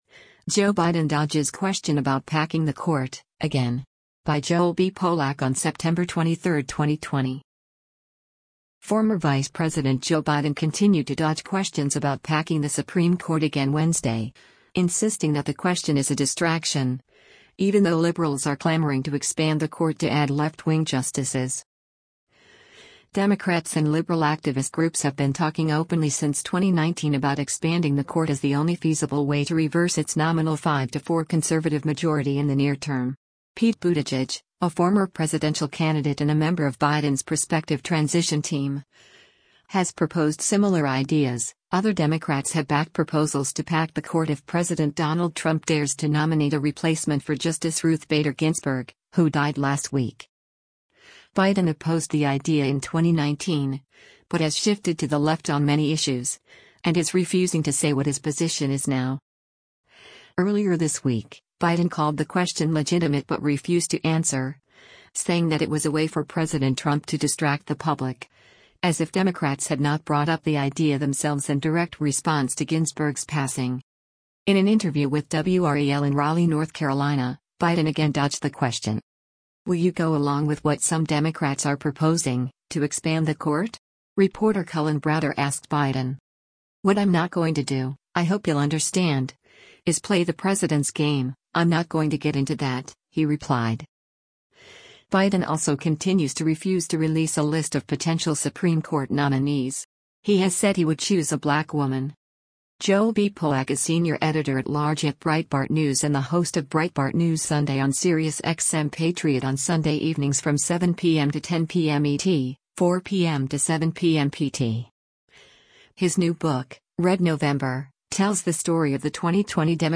In an interview with WRAL in Raleigh, North Carolina, Biden again dodged the question: